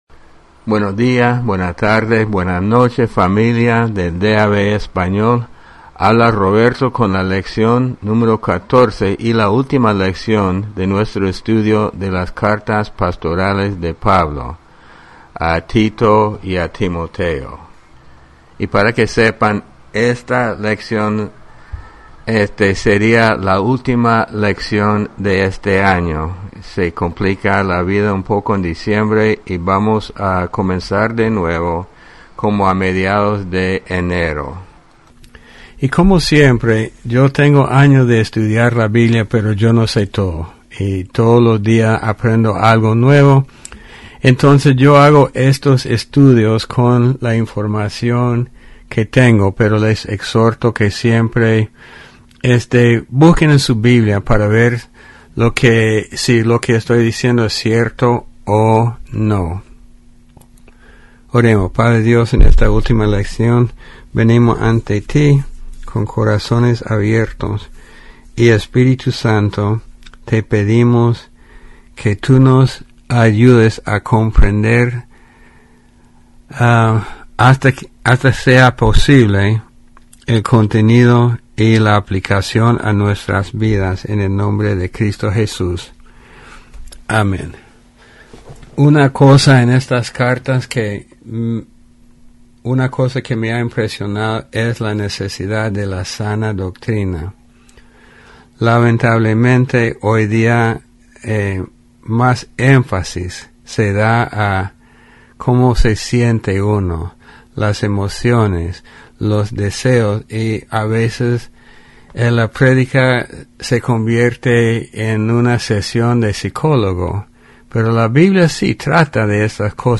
Descargar Audio Lección 14 Las Cartas Pastorales (Timoteo y Tito) Repaso Tito 2 1 .